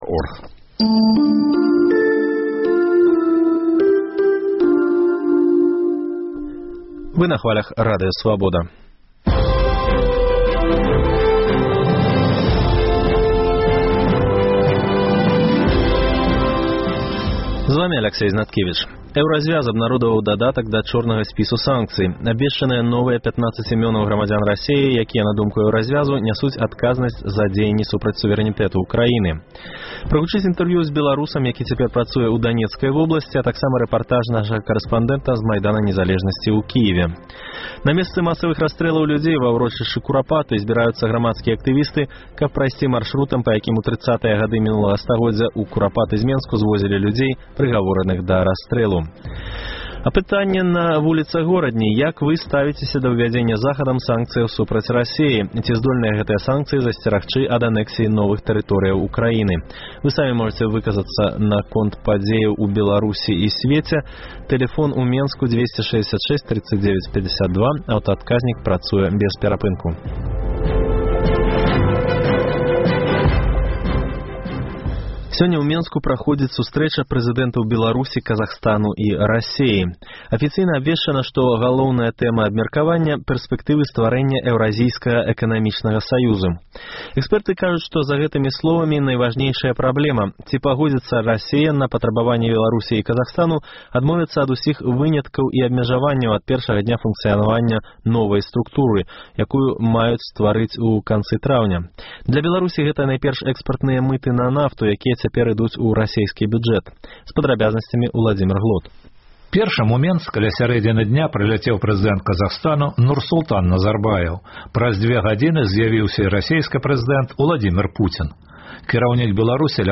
Прагучыць інтэрвію зь беларусам, які цяпер працуе ў Данецкай вобласьці, а таксама рэпартаж нашага карэспандэнта з Майдана незалежнасьці ў Кіеве.